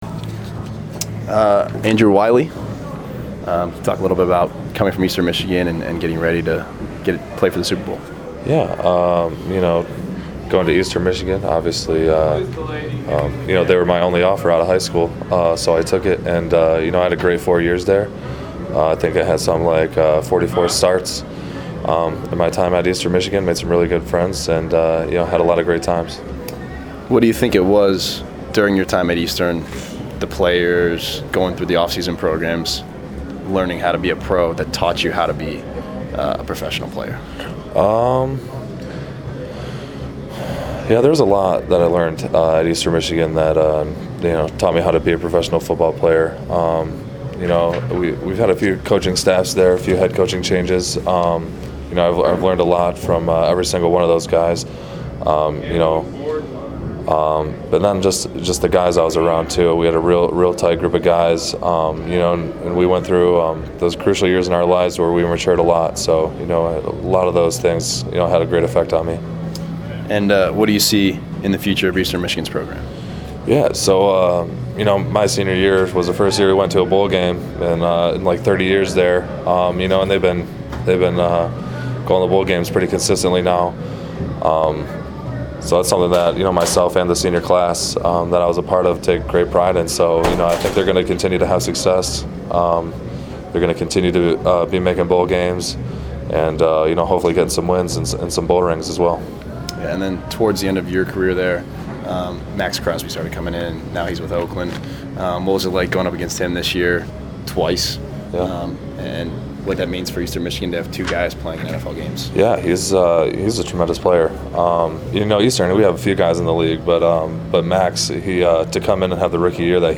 Wylie Interview Audio.